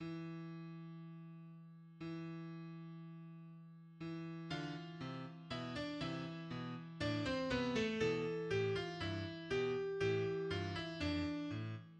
IV. Finale: Bewegt, doch nicht zu schnell (With motion, but not too fast)